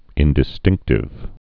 (ĭndĭ-stĭngktĭv)